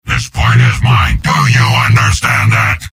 Giant Robot lines from MvM.
{{AudioTF2}} Category:Soldier Robot audio responses You cannot overwrite this file.
Soldier_mvm_m_autocappedcontrolpoint02.mp3